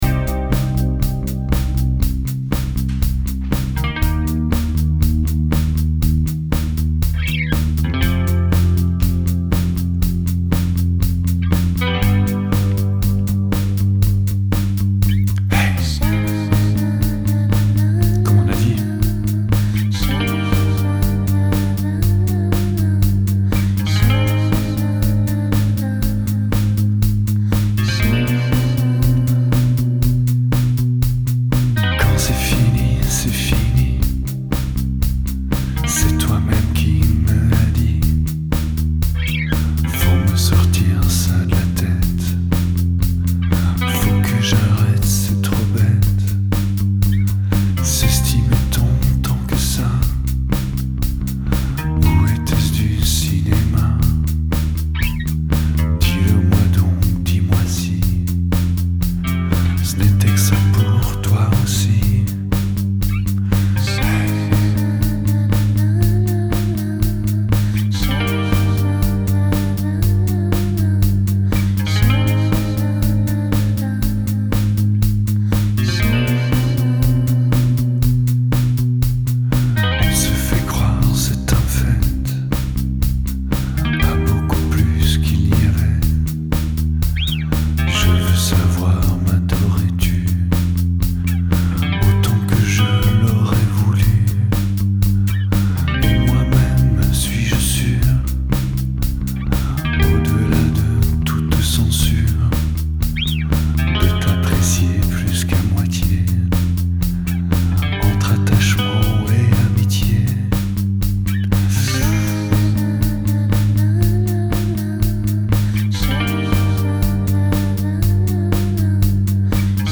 La version est vraiment basique.